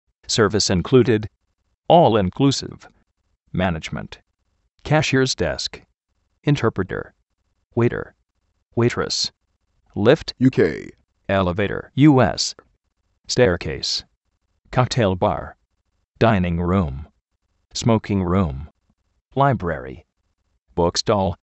férst, sékond, zérd
klás joutél
guést-jáus
bórdin-jáus
risórt
brédendbrékfast (bíendbí)